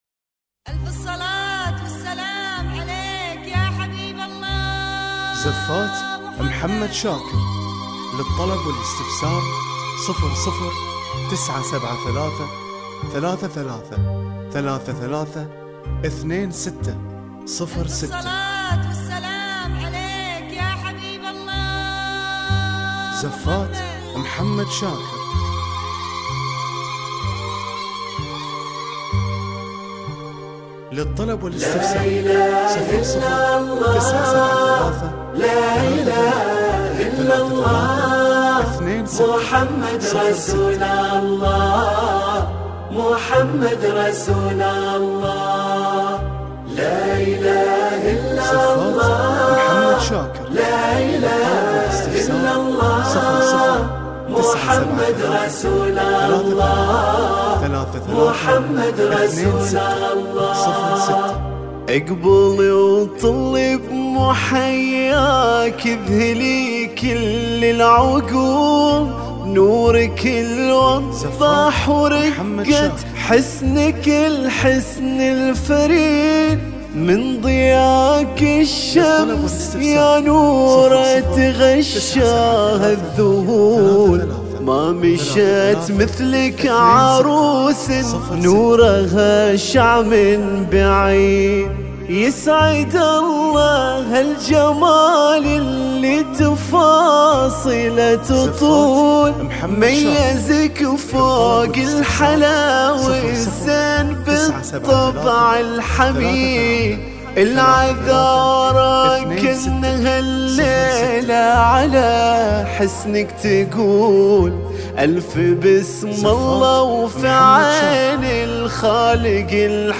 وتتوفر بالموسيقى وبدون موسيقى